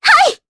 Ophelia-Vox_Attack2_jp.wav